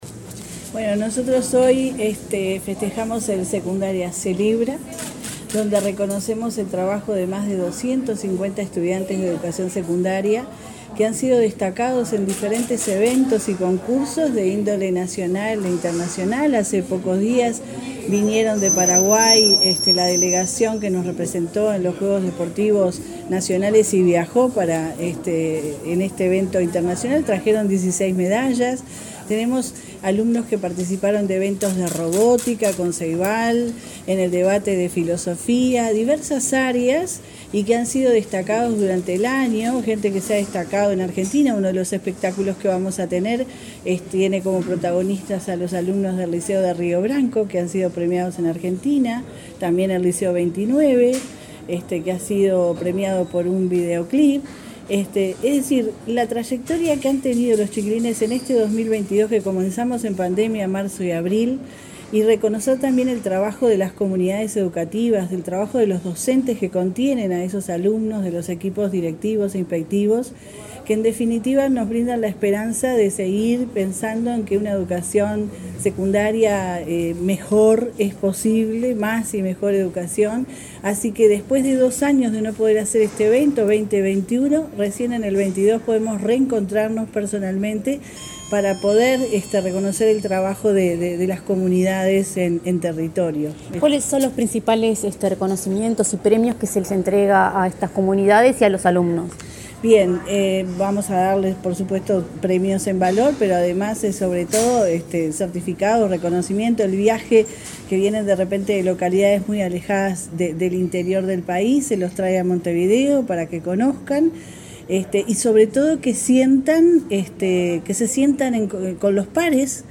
Entrevista a la directora de Secundaria, Jennifer Cherro